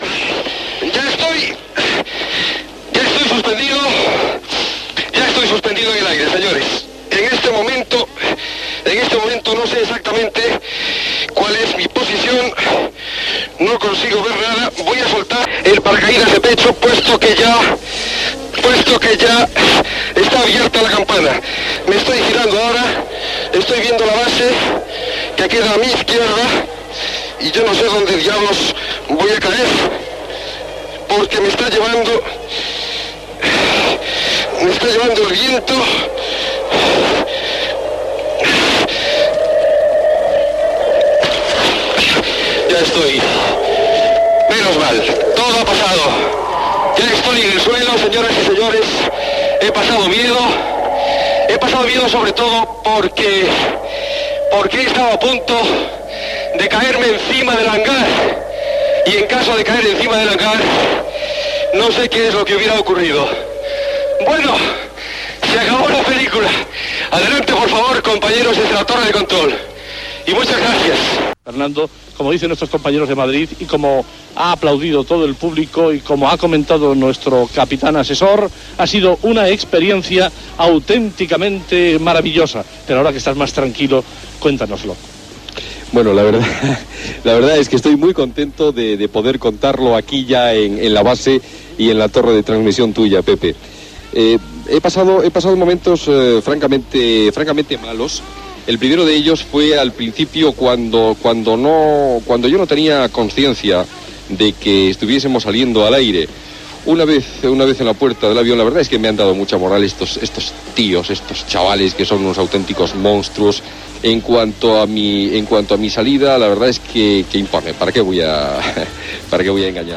Narració durant el descens en paracaigudes, aterratge i valoració final.
Info-entreteniment